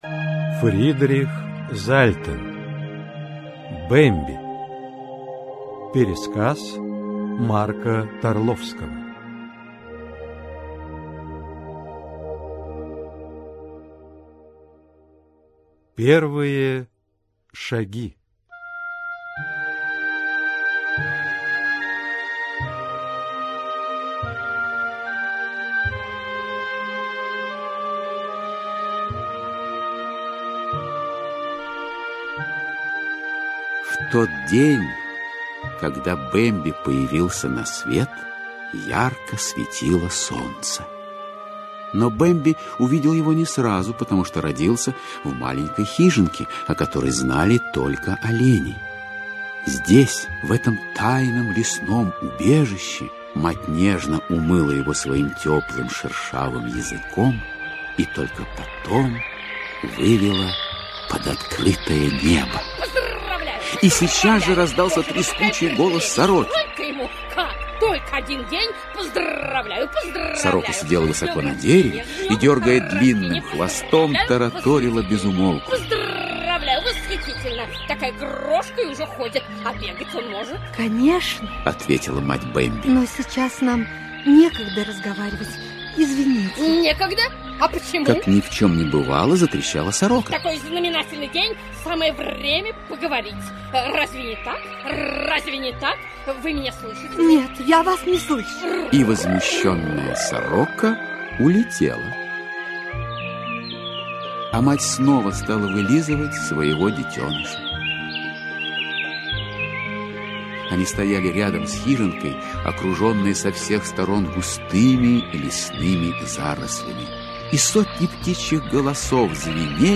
Аудиокнига Бемби (спектакль) | Библиотека аудиокниг
Aудиокнига Бемби (спектакль) Автор Сборник Читает аудиокнигу Василий Бочкарев.